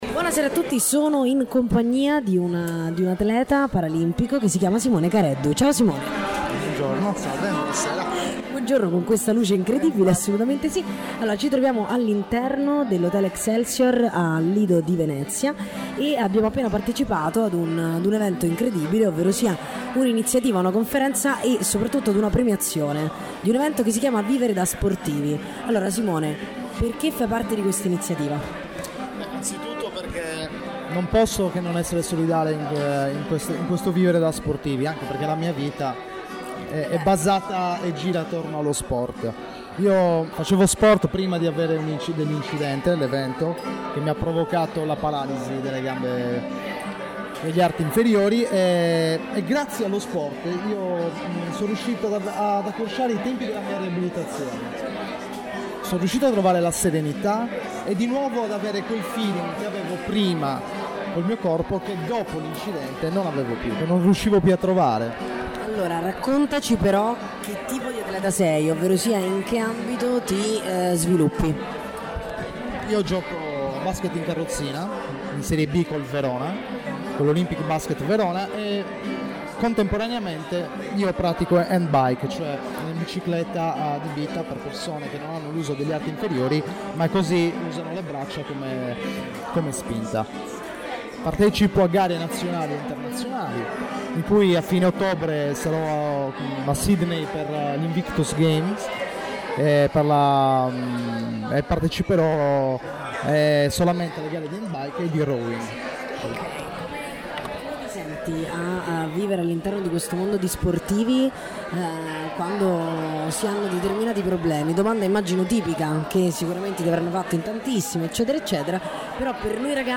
Intervista a